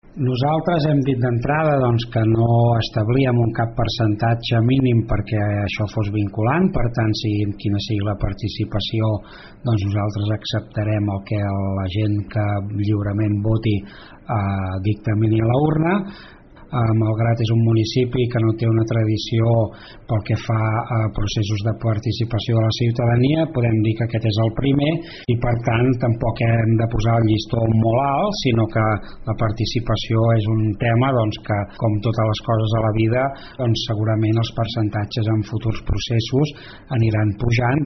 El regidor de Participació Ciutadana, Ramir Roger, assegura que s’acceptarà el resultat sigui quin sigui el percentatge de participació. Malgrat de Mar, diu, és un municipi pràcticament sense tradició en participació ciutadana, pel que de moment se’n fa una valoració positiva.